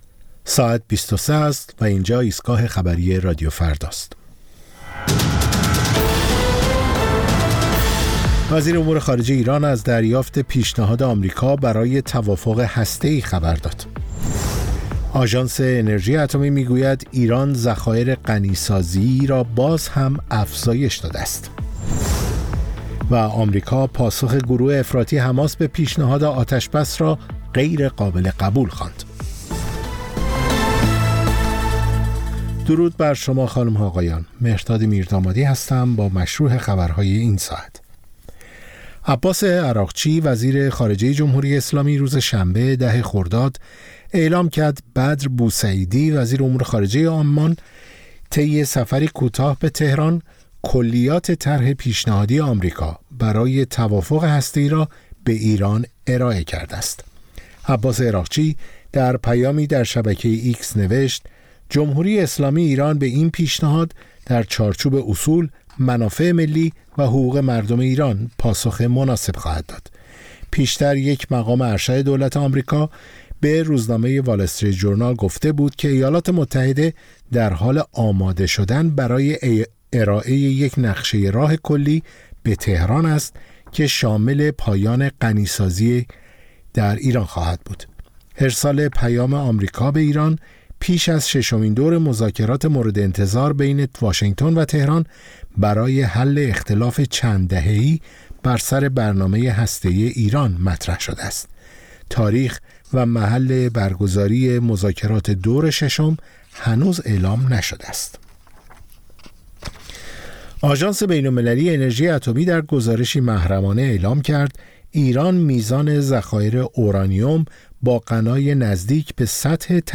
سرخط خبرها ۲۳:۰۰